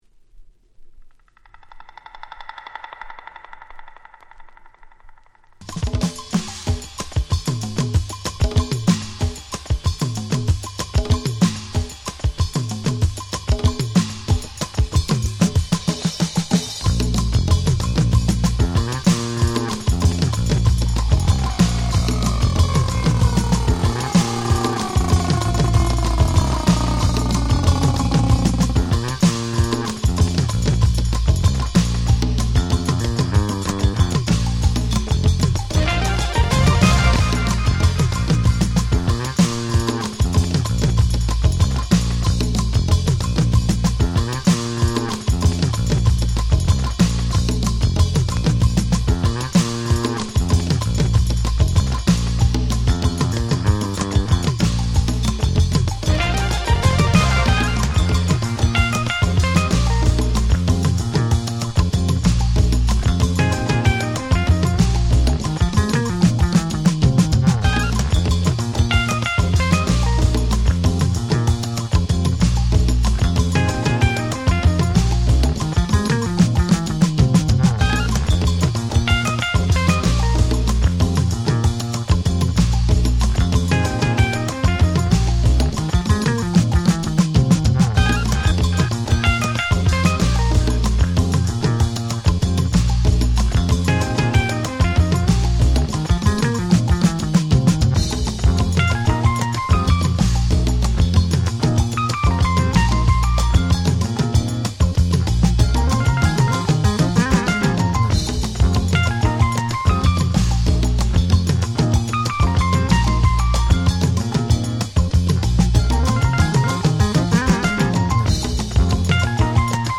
21' Very Nice Re-Edit !!
Jazz ジャズ